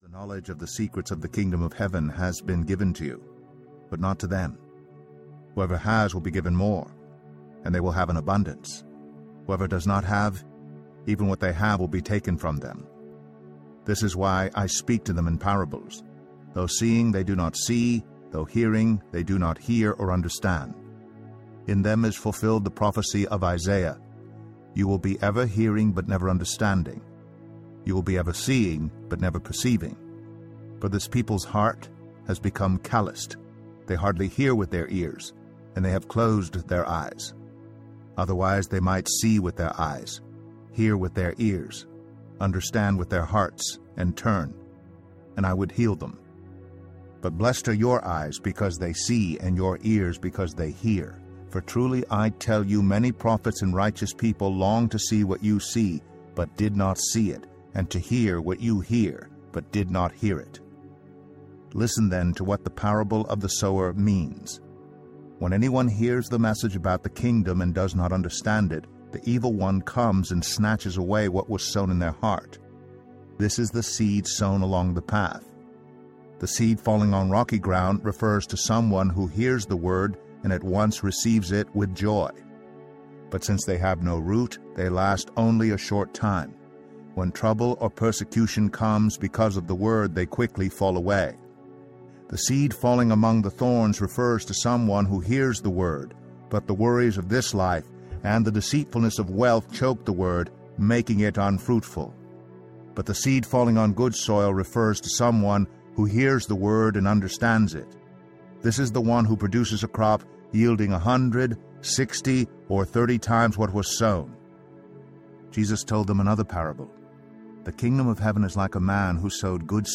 Read in a single voice with subtle background music
18.25 Hrs. – Unabridged